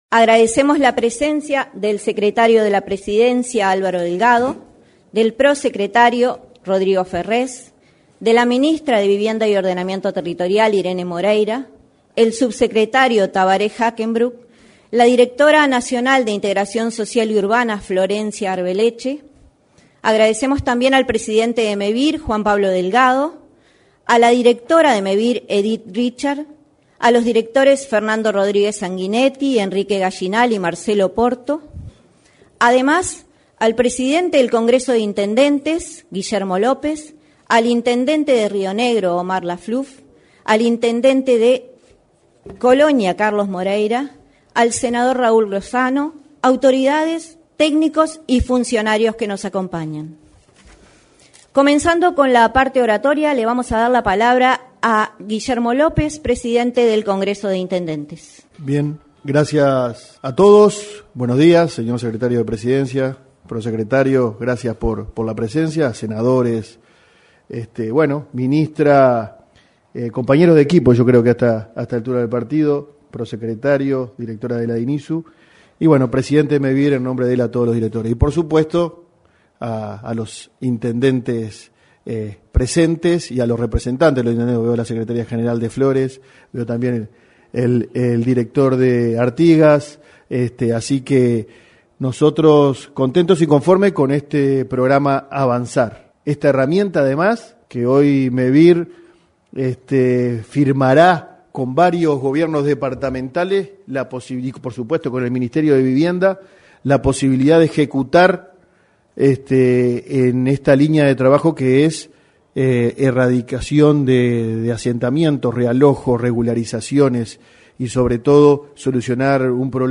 Este lunes 4, en el salón de actos de la Torre Ejecutiva, se realizó la presentación de un acuerdo específico para elaborar proyectos del plan Avanzar